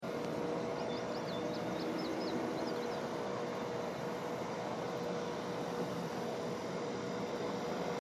Spotted Sandpiper feeds with Indigo sound effects free download
Spotted Sandpiper feeds with Indigo Bunting in background birdsounds